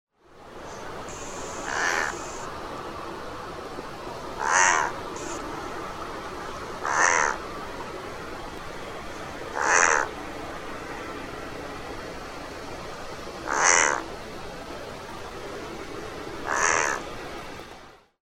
На этой странице вы можете послушать и скачать звуки гагарки – морской птицы, известной своим характерным голосом.
Звук гагарки у водоема в дикой природе